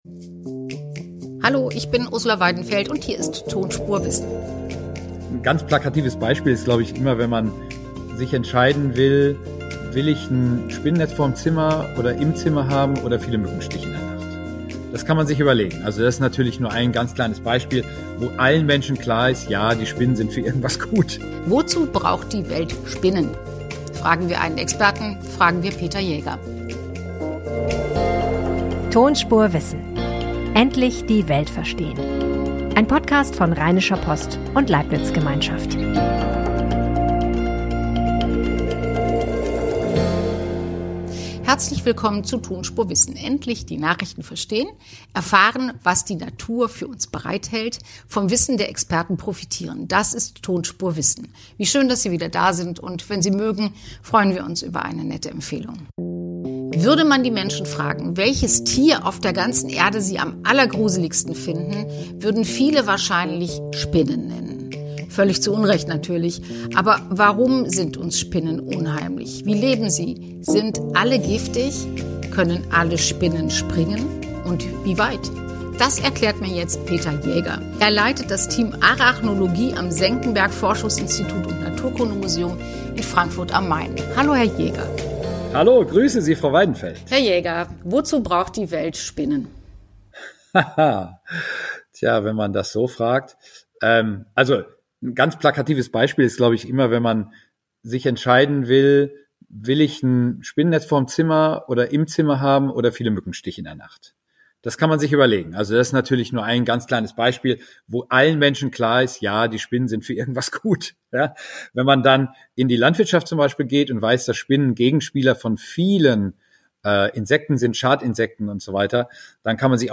Ein Spinnenforscher erklärt, warum die Tiere faszinierend und nützlich sind.